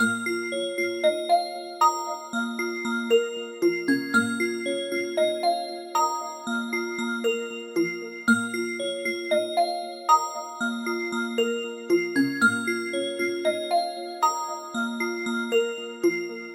描述：我首先创造了一个声音，然后添加了混响和集成的回声以获得更多的共鸣，以及加速和减速节奏的交替以及最后的淡入淡出。
声道立体声